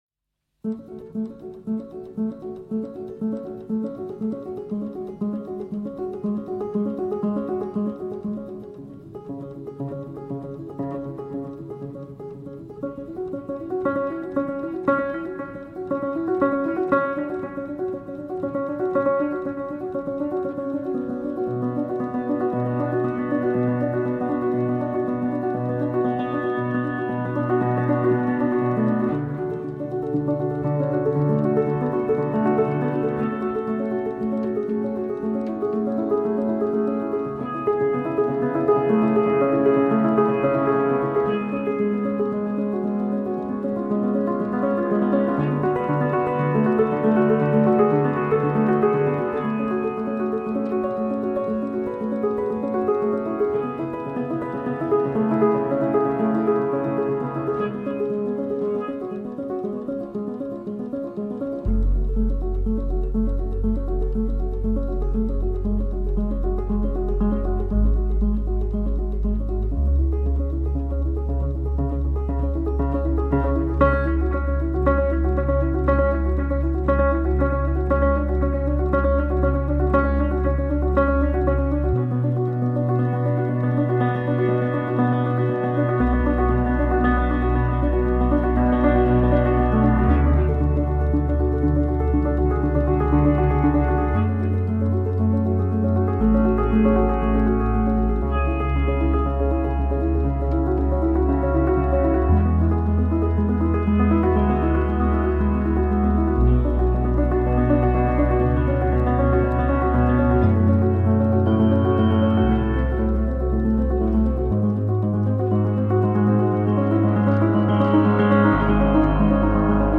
پیانو سولو Piano solo